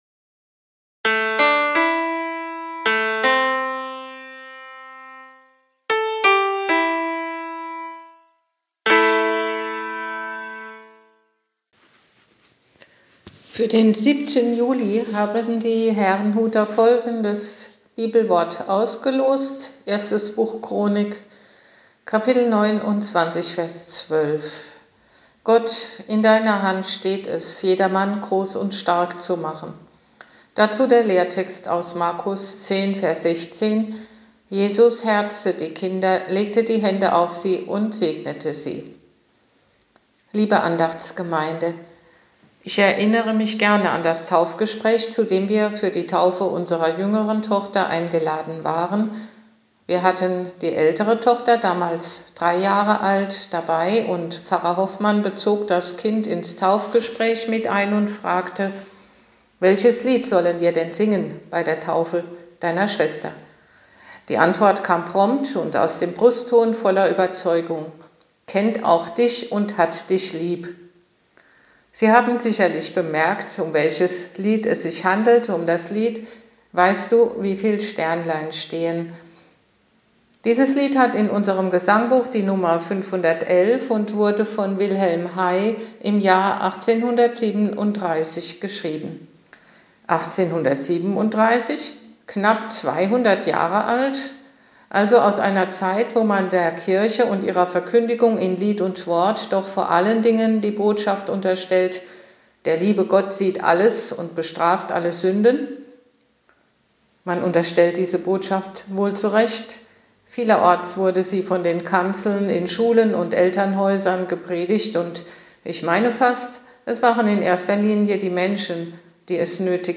Losungsandacht für Donnerstag, 07.07.2022